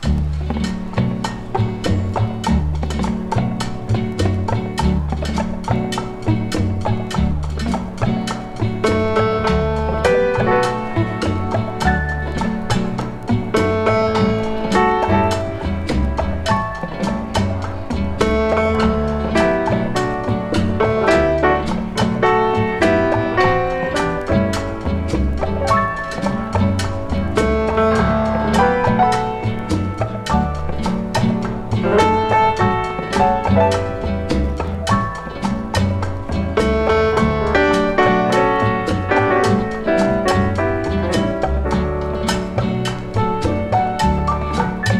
彼らのヒット曲が目白押し、ピアノとスウィートなアンサンブルも聴きどころ多い充実盤。
Jazz, Pop, Easy Listening　USA　12inchレコード　33rpm　Stereo